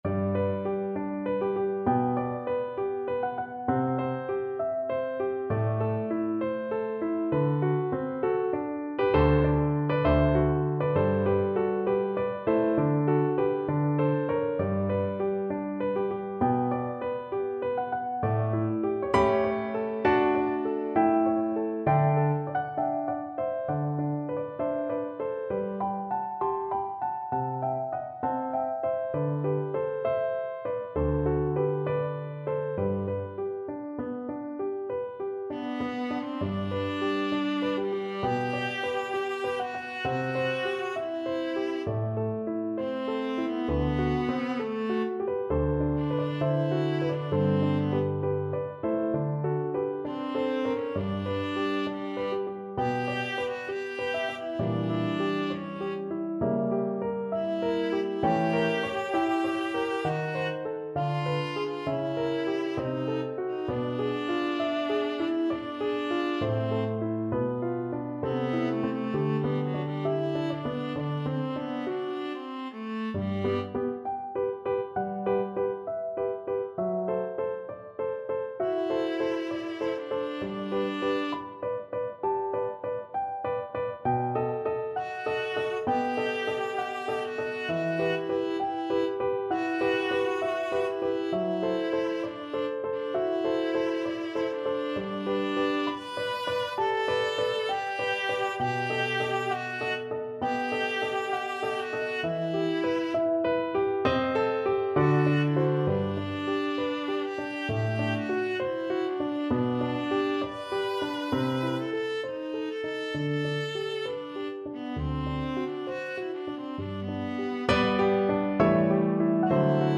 Viola
4/4 (View more 4/4 Music)
G major (Sounding Pitch) (View more G major Music for Viola )
Andantino =66 (View more music marked Andantino)
Classical (View more Classical Viola Music)